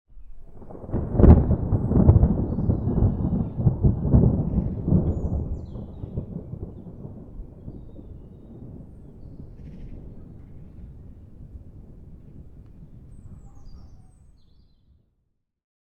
thunderfar_1.ogg